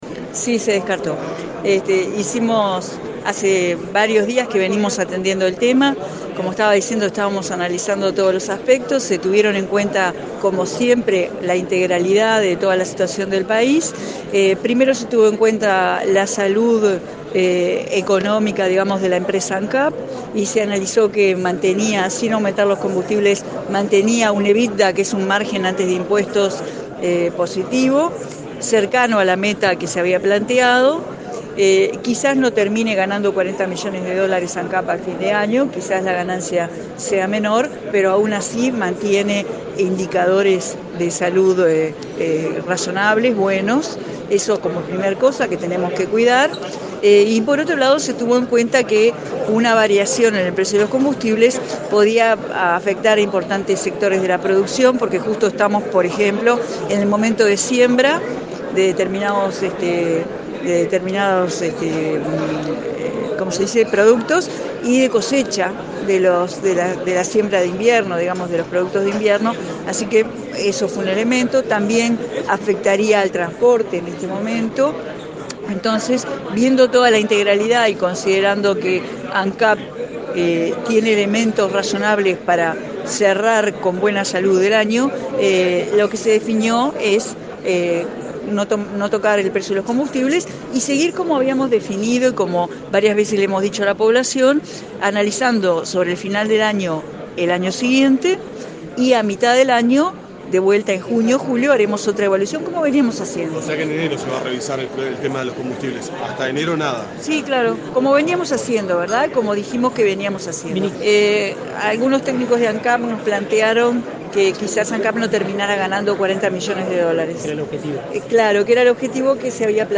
Los buenos indicadores económicos que acumuló Ancap en los últimos tiempos, la posibilidad de continuar con la cancelación de deudas del ente y la incidencia en el sector productivo de una suba de las tarifas en temporada de cosechas agrícolas fundamentan la decisión de no subir el precio de los combustibles, dijo la ministra Cosse este martes 30 en la Torre de las Telecomunicaciones.